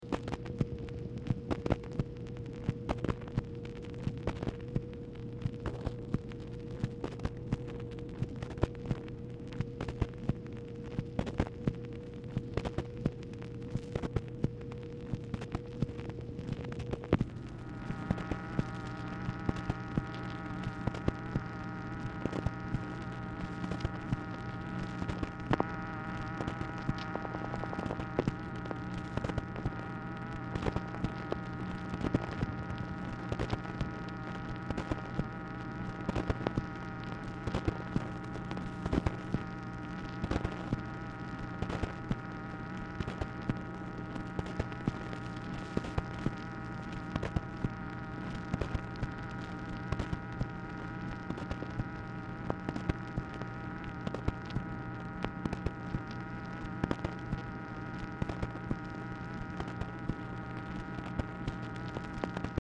Telephone conversation # 4689, sound recording, MACHINE NOISE, 8/4/1964, time unknown | Discover LBJ
Format Dictation belt
White House Telephone Recordings and Transcripts Speaker 2 MACHINE NOISE